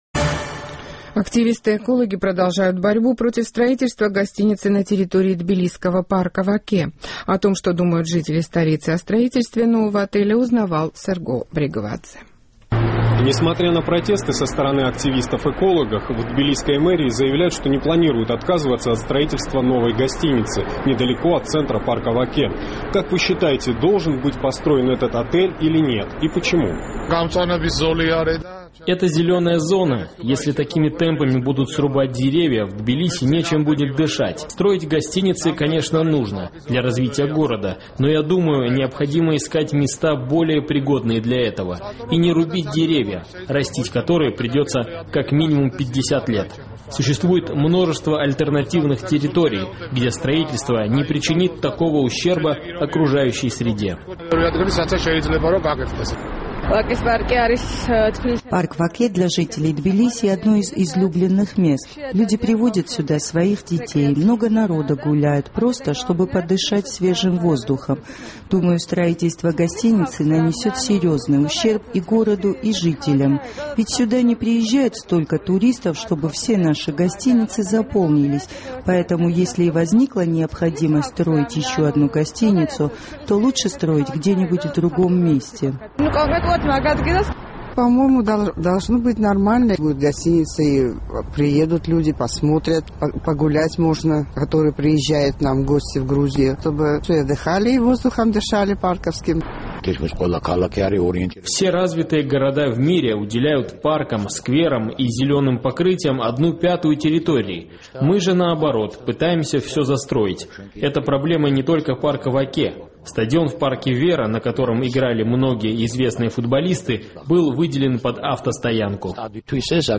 Активисты-экологи продолжают борьбу против строительства гостиницы на территории тбилисского парка Ваке. Наш корреспондент интересовался у жителей столицы их мнением по этому поводу.